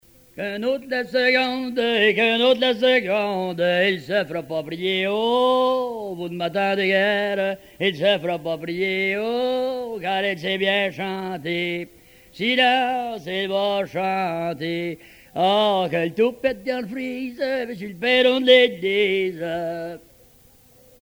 Genre brève
Pièce musicale éditée